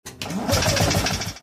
engine_start.mp3